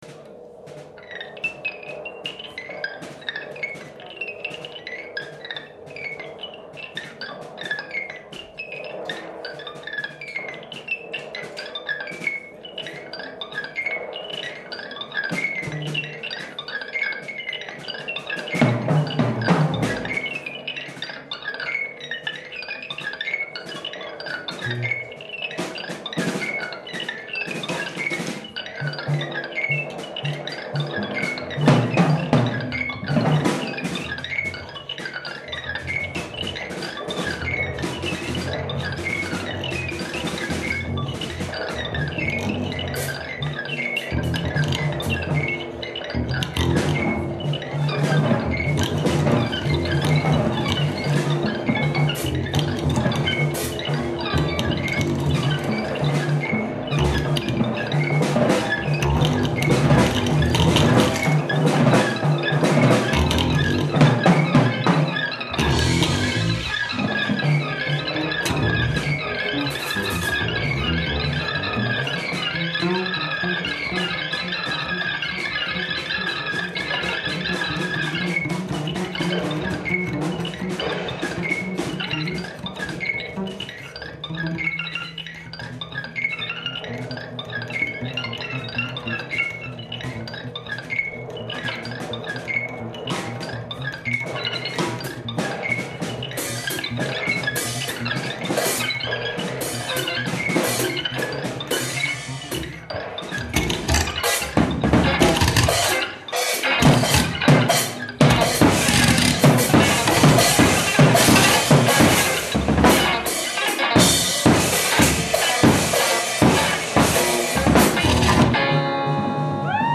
kontrabas, efekti, elektronika
elektri�na kitara, efekti, hardware
bobni, ropotulje
svobodno-improvizirajo�ih glasbenih akcij